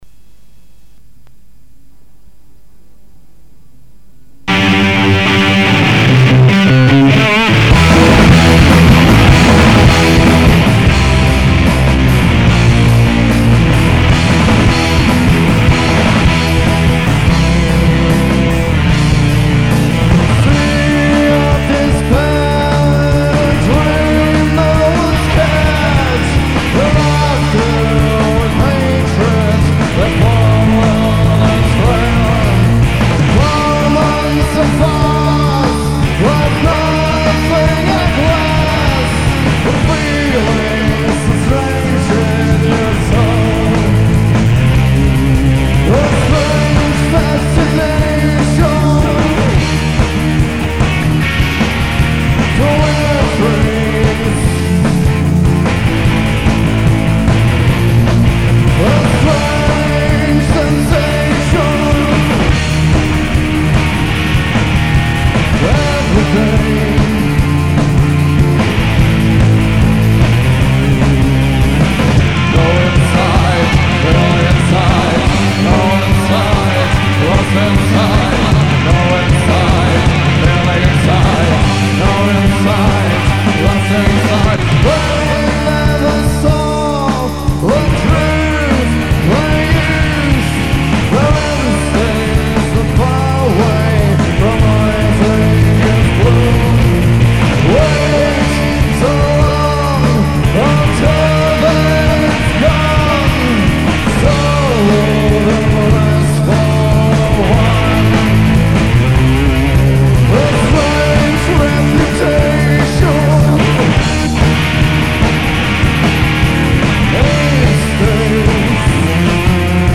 Gitarre
Schlagzeug
live 1993
Die Live-Version